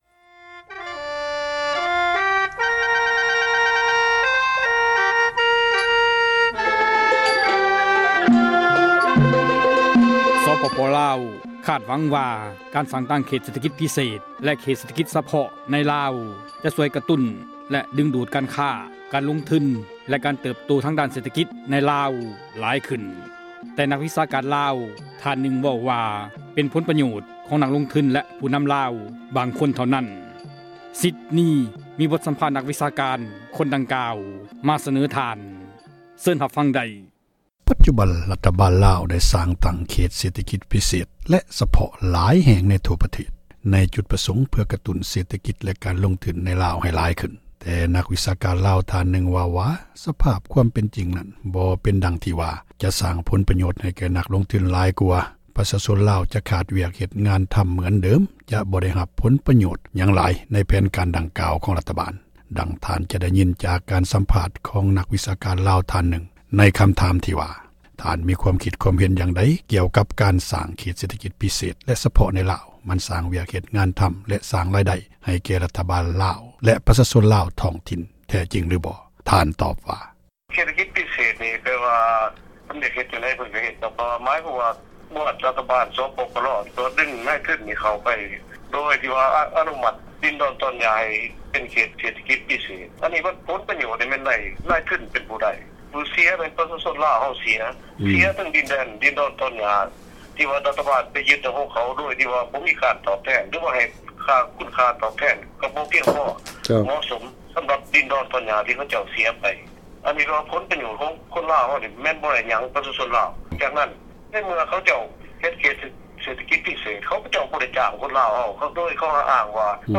ບົດ ສຳພາດ